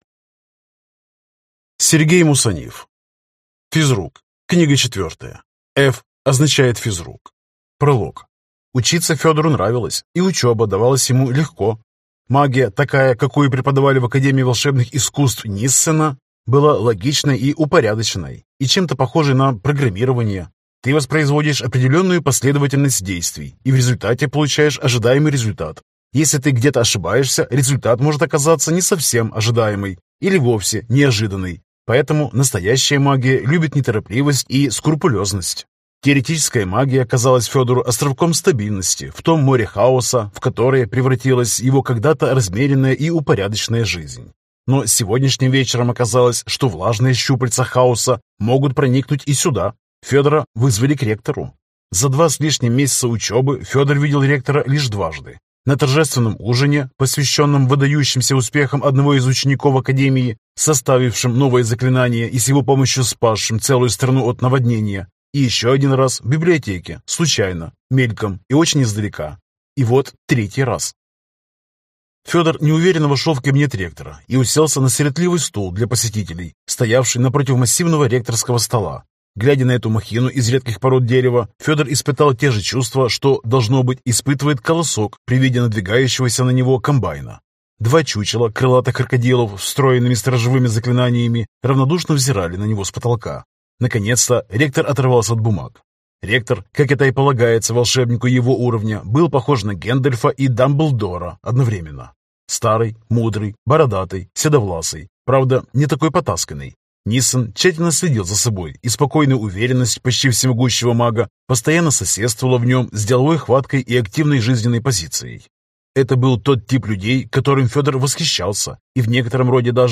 Аудиокнига Ф означает Физрук. Книга 4 | Библиотека аудиокниг